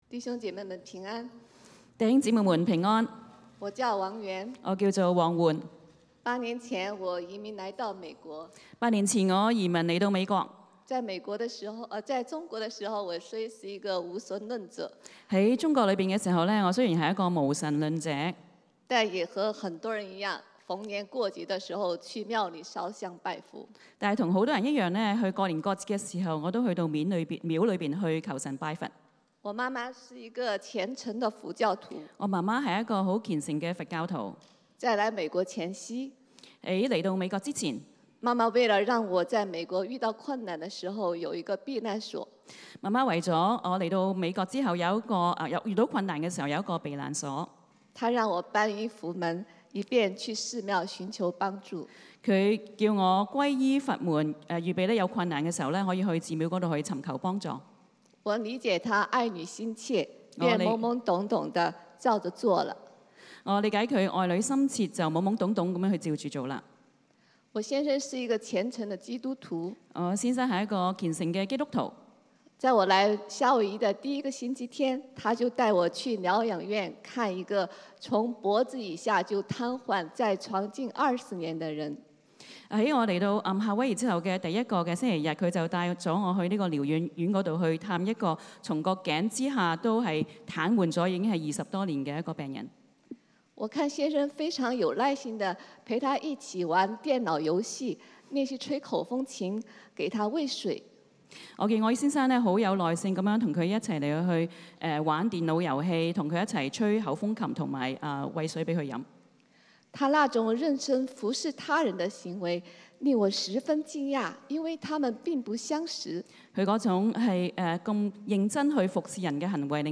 福音主日 講道經文